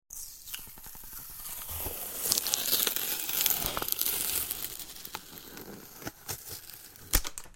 3. Медленное отклеивание липкой ленты от предмета